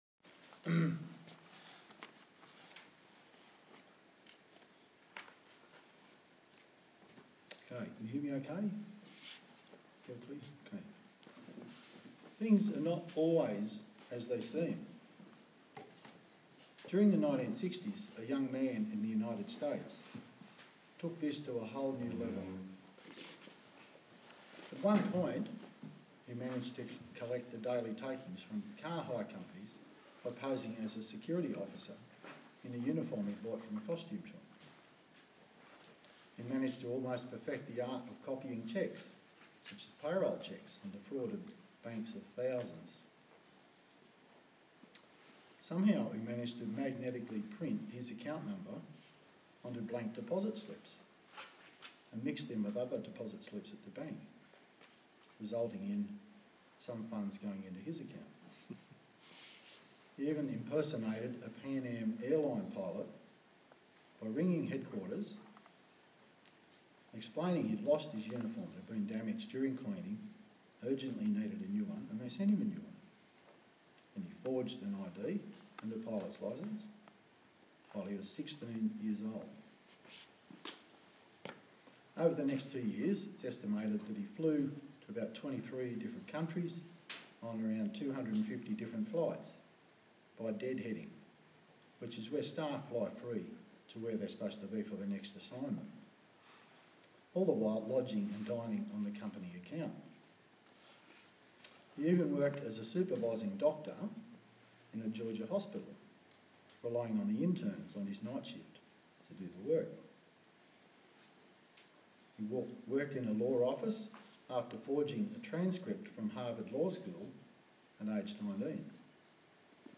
Service Type: TPC@5